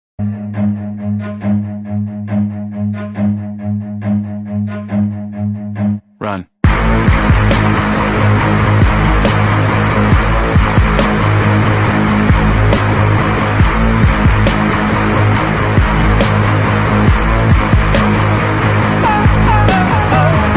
Sound Effect: Run
sound-effect-run-2.mp3